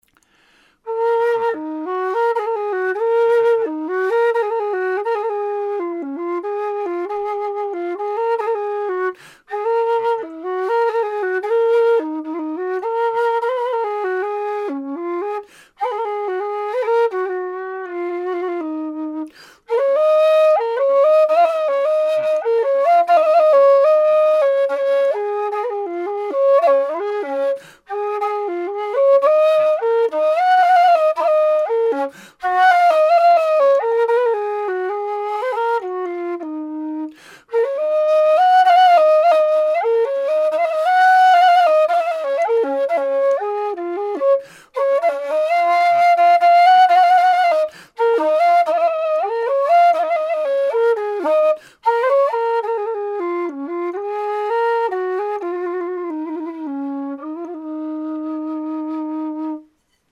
low Db/C# whistle - 200 GBP
made out of thin-walled aluminium tubing with 23mm bore
LowDb-sample.mp3